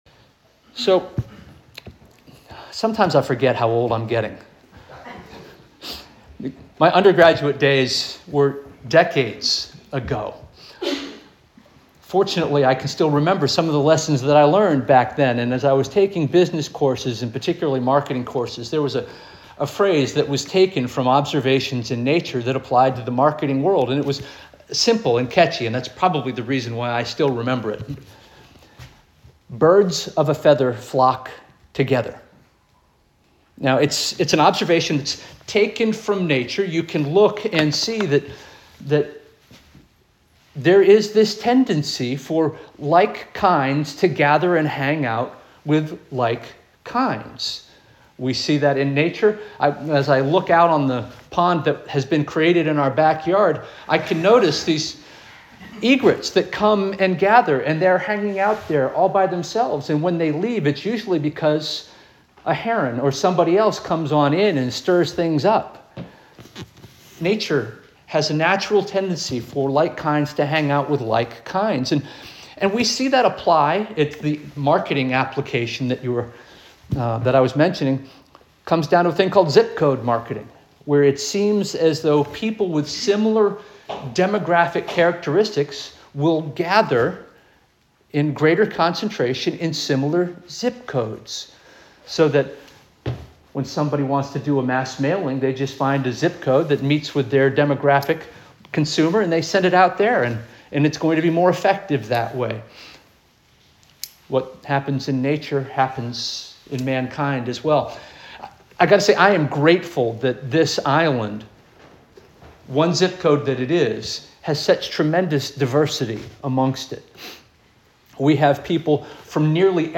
October 12 2025 Sermon - First Union African Baptist Church